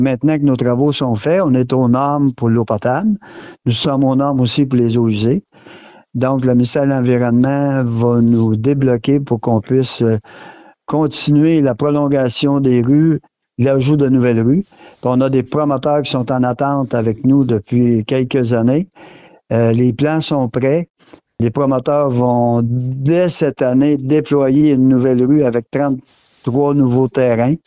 Le maire, Raymond Noël, a expliqué que la Municipalité travaillait sur les redditions de comptes.